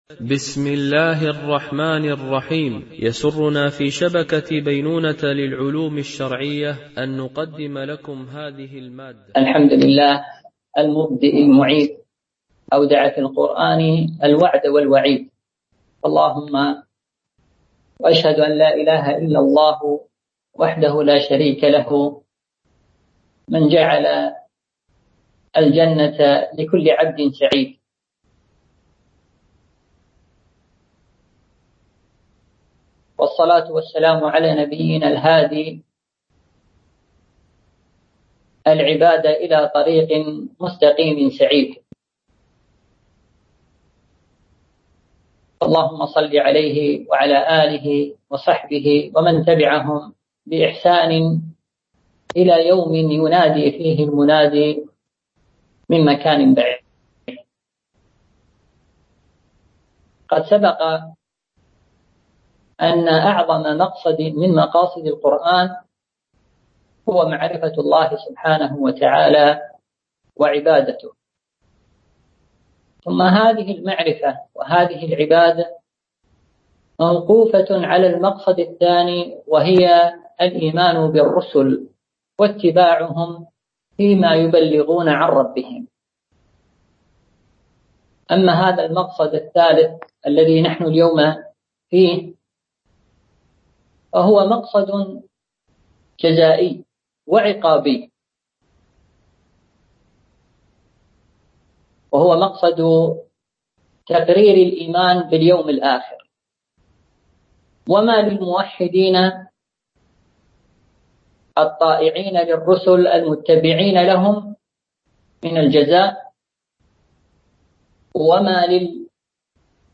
سلسلة محاضرات في تفسير القرآن الكريم - المحاضرة 8 ( مقصد القرآن في إثبات اليوم الآخر)